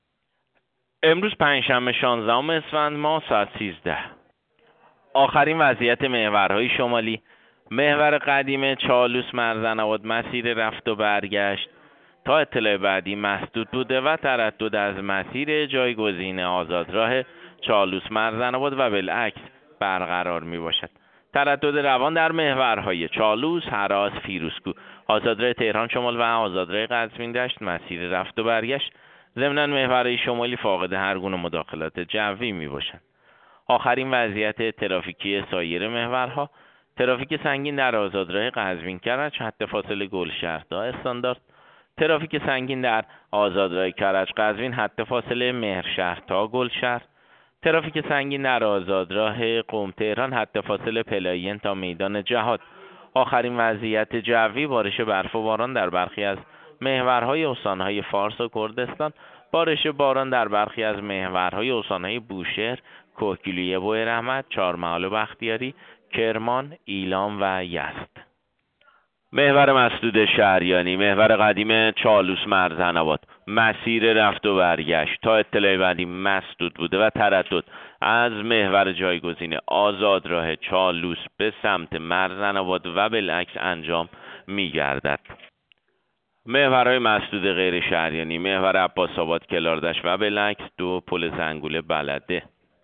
گزارش رادیو اینترنتی از آخرین وضعیت ترافیکی جاده‌ها ساعت ۱۳ شانزدهم اسفند؛